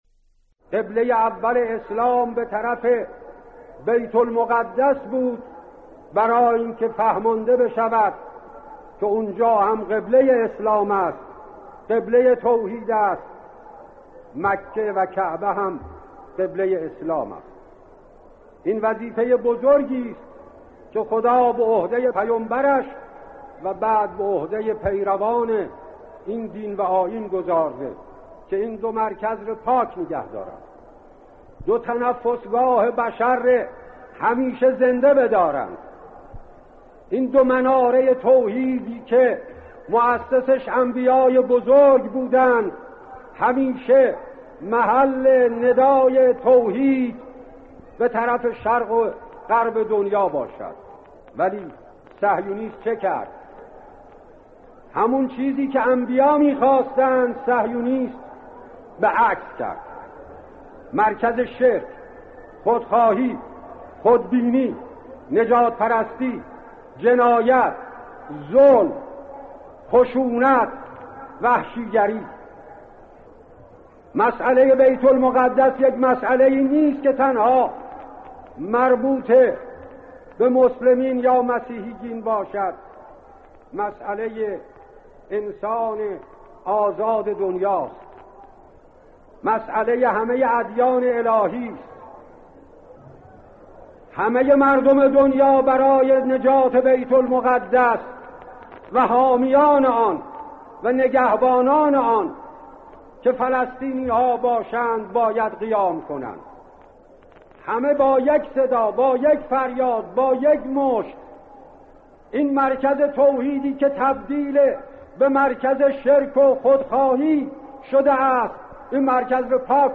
سخنرانی شهید آیت الله طالقانی در خصوص مسئله بیت المقدس